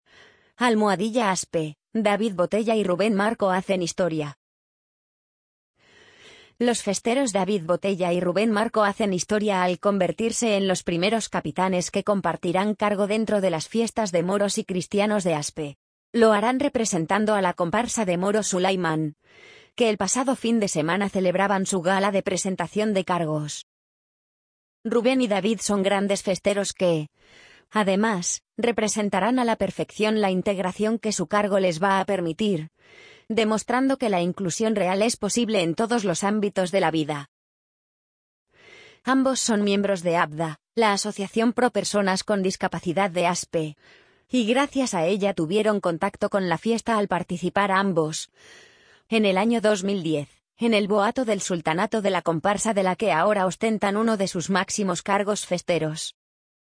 amazon_polly_58066.mp3